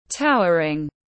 Cao chót vót tiếng anh gọi là towering, phiên âm tiếng anh đọc là /ˈtaʊə.rɪŋ/ .
Towering /ˈtaʊə.rɪŋ/